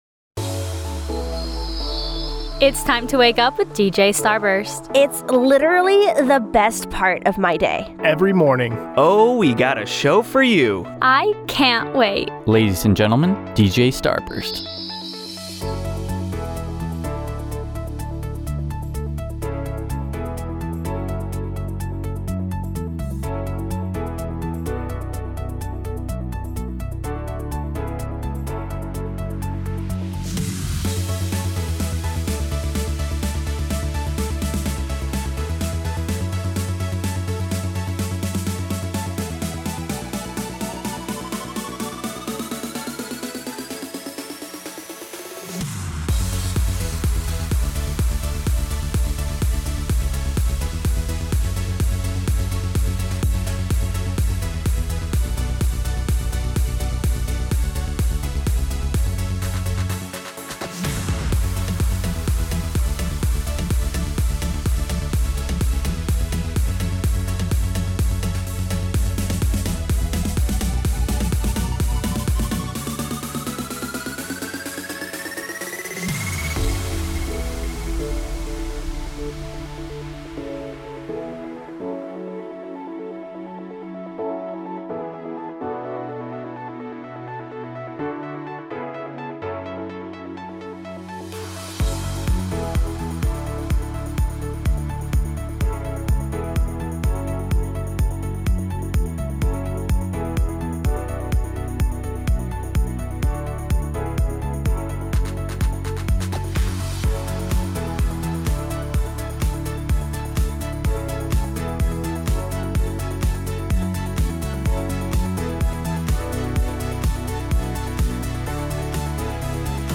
Opening Show Liner- Morning Magic